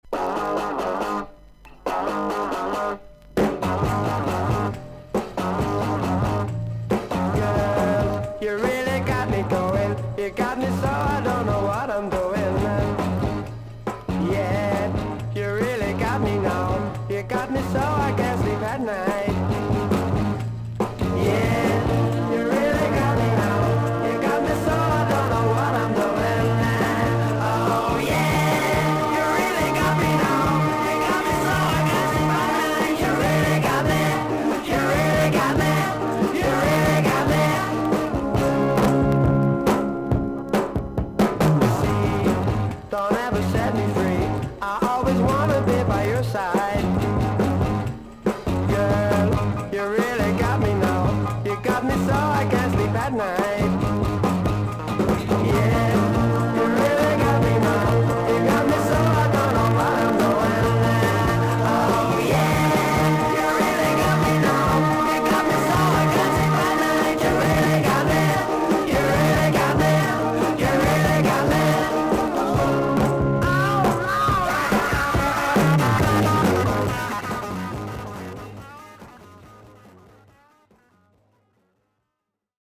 VG++〜VG+ 少々軽いパチノイズの箇所あり。クリアな音です。